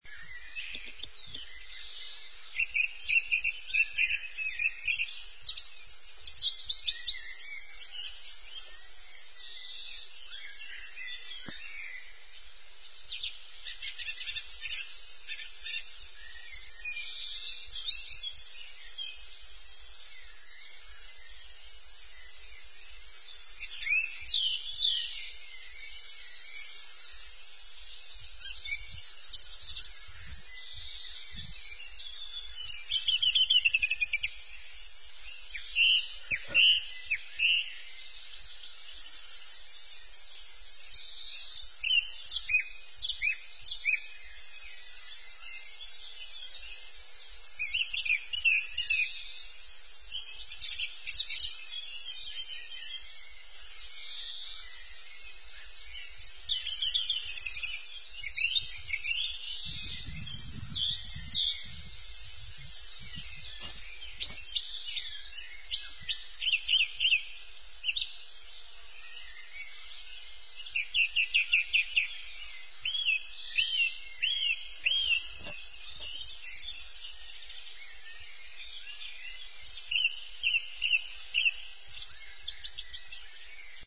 ajouter le son enregistré sur place
add birds songs recorded here
OISEAUX la roch 1.mp3